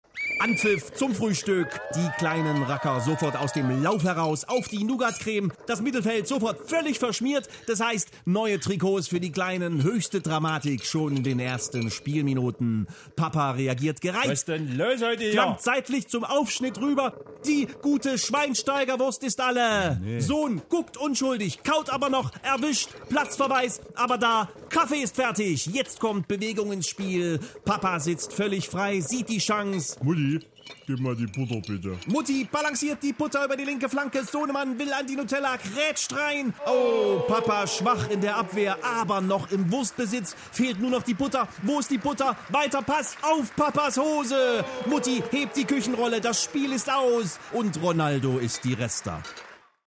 Er kann nicht anders: Er muss alles was morgens in Deiner Familie passiert kommentrieren: Als Fußballspiel!